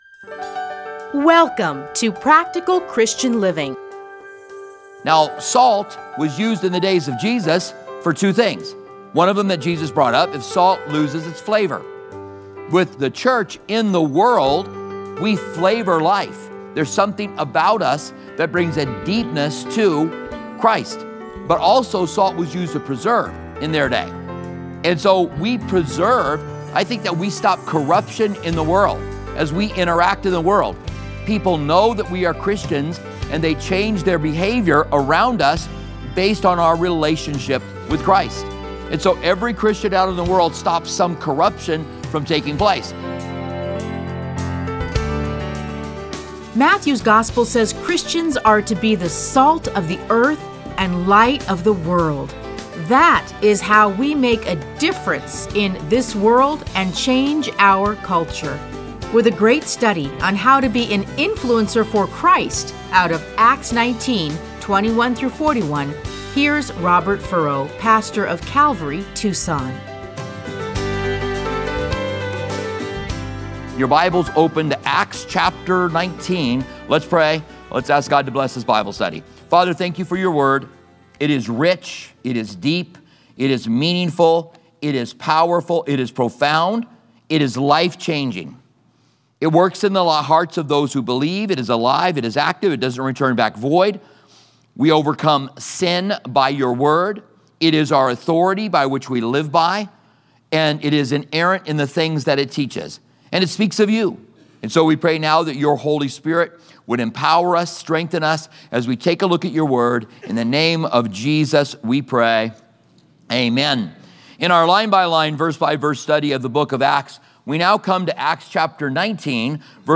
Listen to a teaching from Acts 19:21-41.